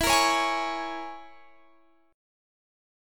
Listen to E6b5 strummed